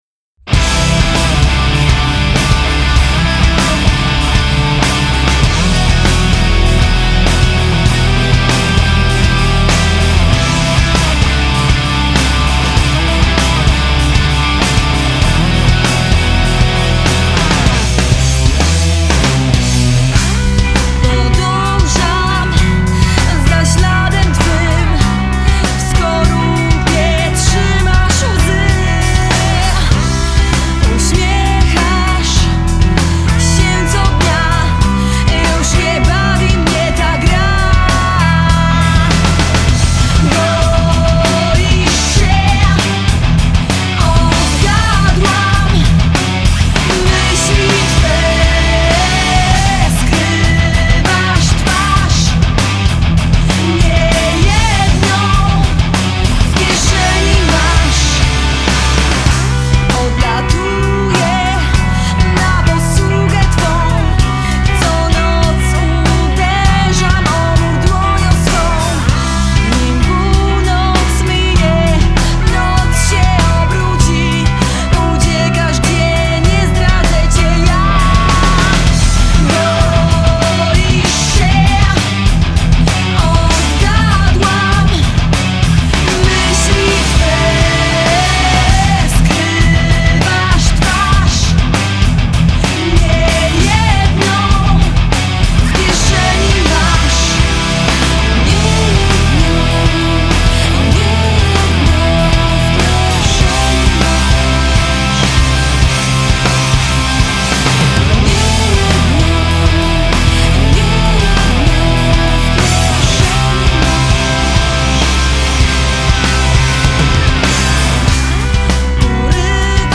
Gatunek: Rock
Vocals
Guitars
Bass
Drums